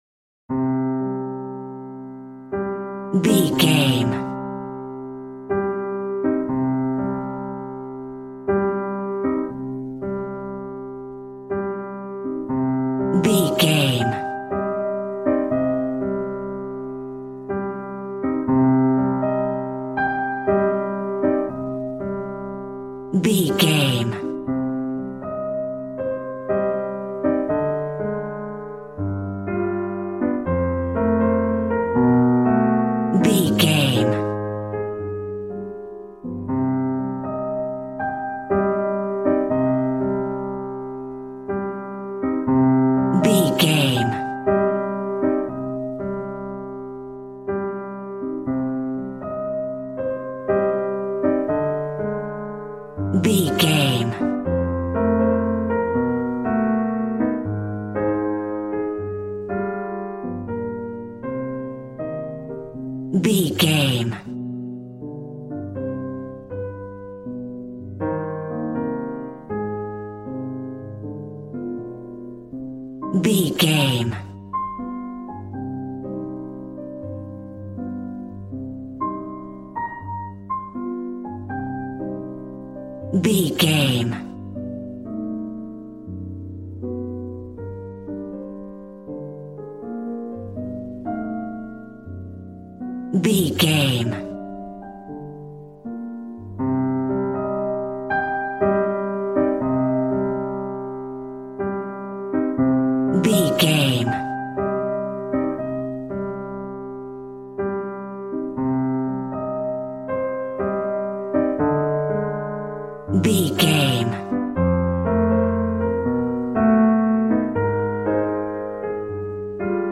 Aeolian/Minor
cool
piano